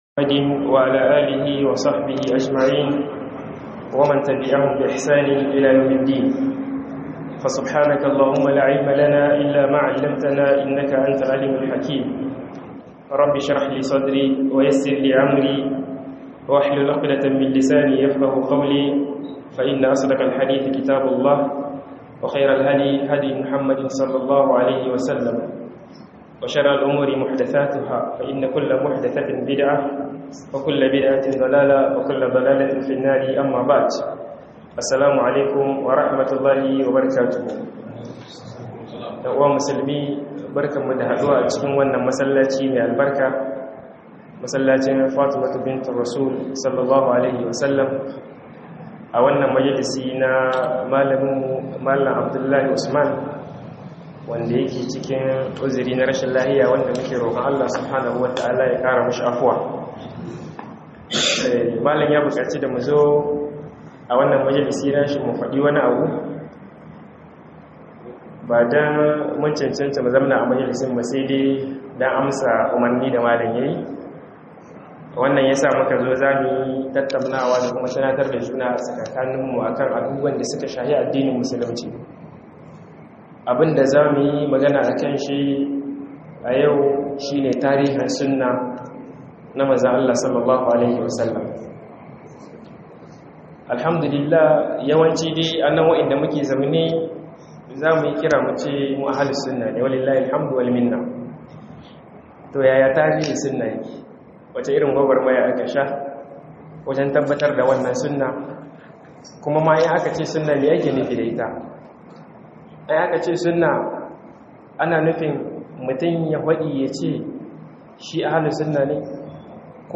TARIHIN SUNNAH - MUHADARA